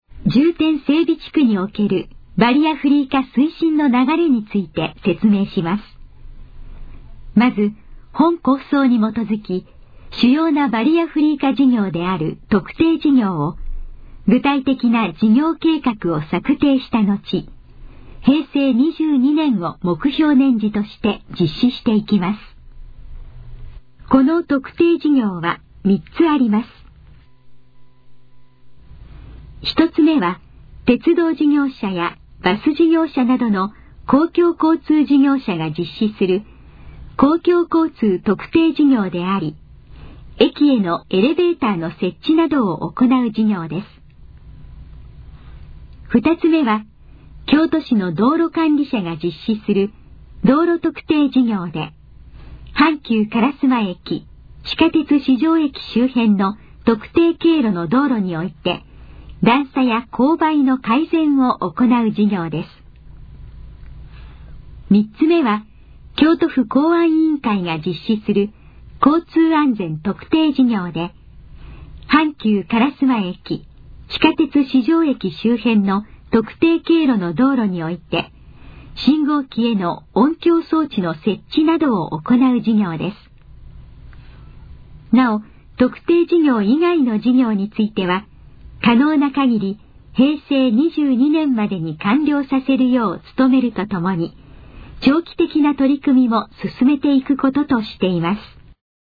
このページの要約を音声で読み上げます。
ナレーション再生 約199KB